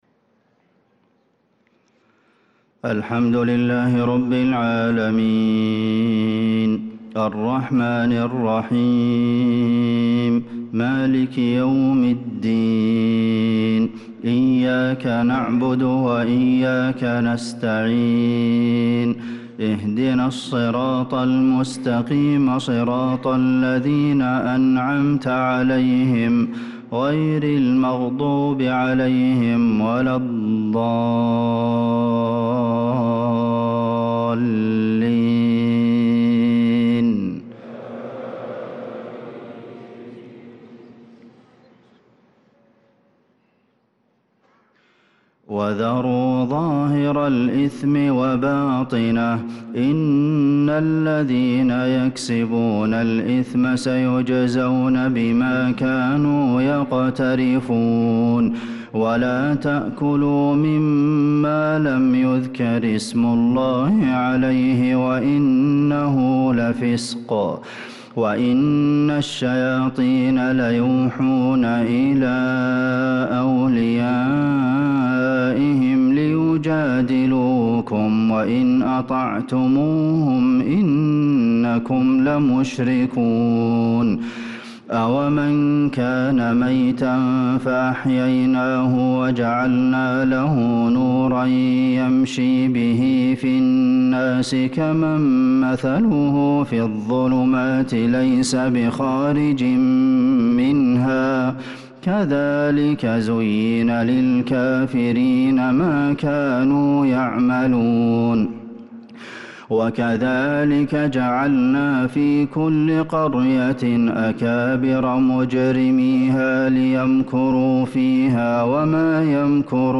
صلاة العشاء للقارئ عبدالمحسن القاسم 23 شوال 1445 هـ
تِلَاوَات الْحَرَمَيْن .